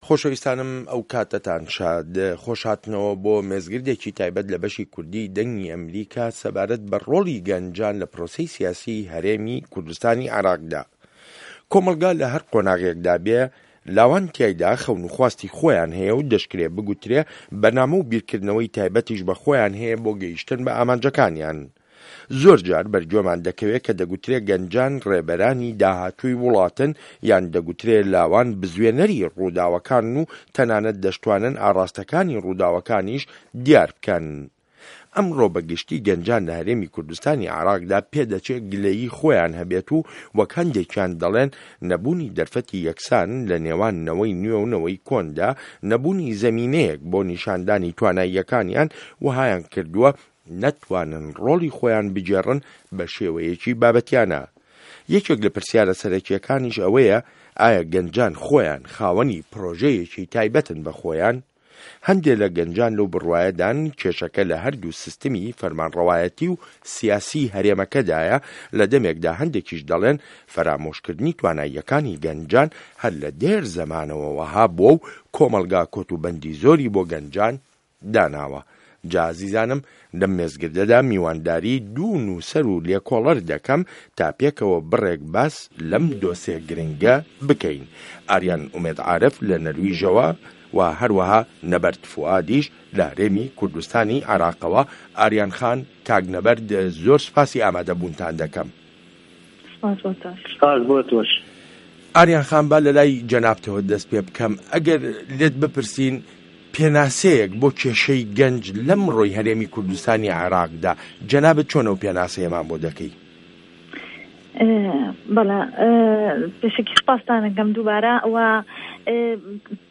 مێزگرد: ڕۆڵی گه‌نجان له‌ پرۆسه‌ی سیاسی هه‌رێمی کوردستانی عێراقدا